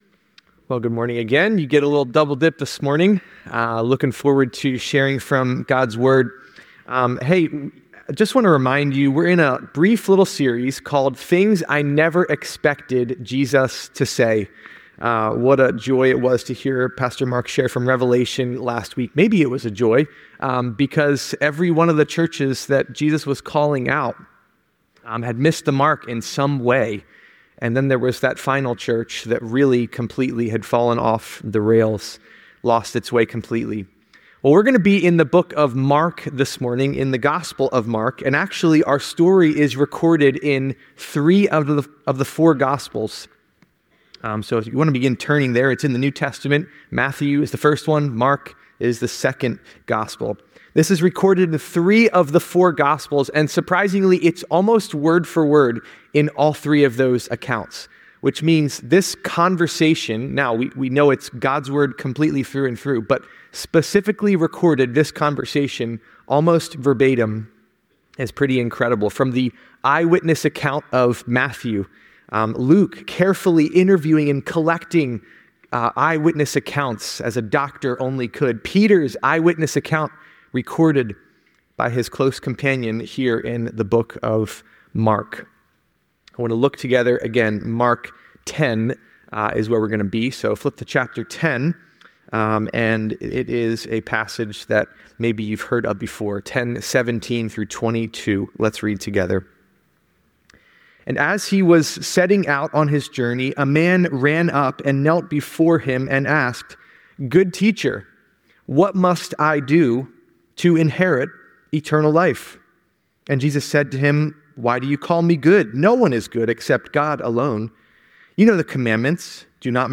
The story prompts reflections on eternal life, the nature of true discipleship, and the condition of one’s heart. Various scriptures are cited to highlight the meaning of eternal life and the pitfalls of relying on one's own righteousness. The sermon concludes with an invitation to surrender one's heart to Jesus, stressing that true satisfaction and eternal life come from this surrender and relationship with Christ.